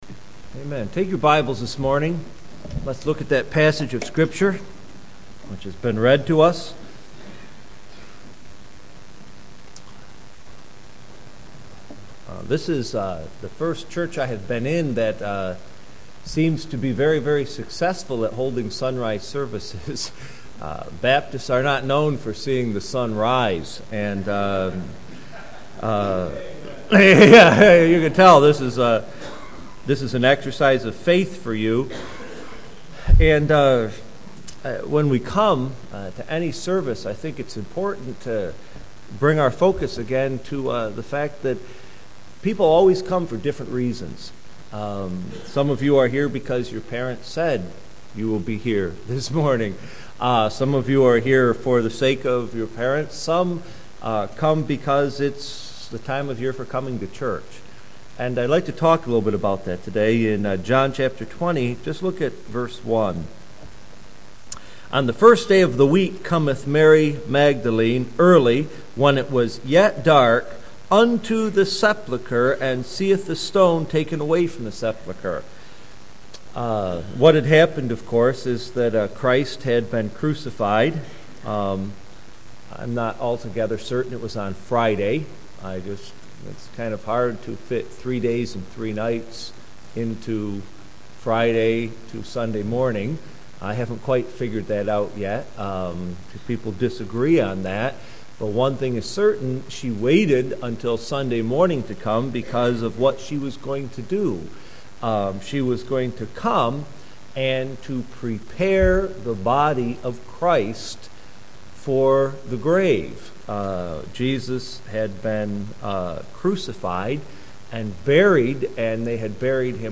Sunrise Service